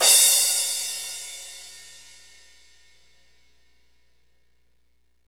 Index of /90_sSampleCDs/Northstar - Drumscapes Roland/CYM_Cymbals 3/CYM_H_H Cymbalsx